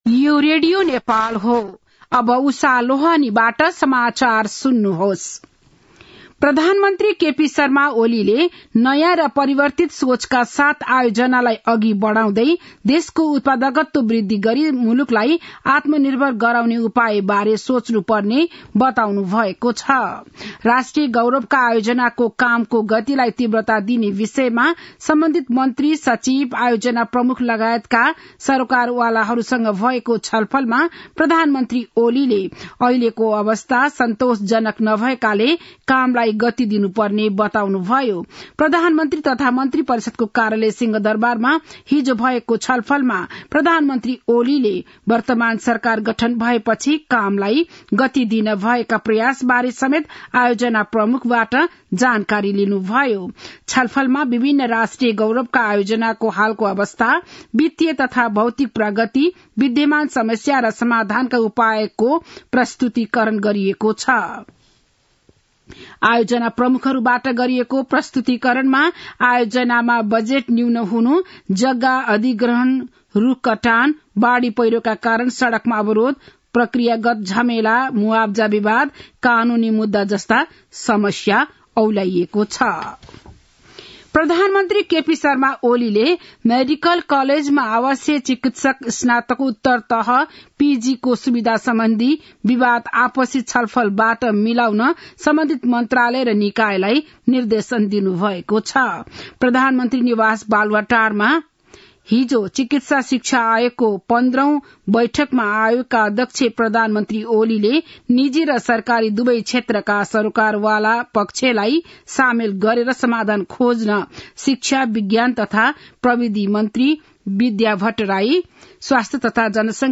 बिहान ११ बजेको नेपाली समाचार : ११ मंसिर , २०८१
11-am-nepali-news-1-8.mp3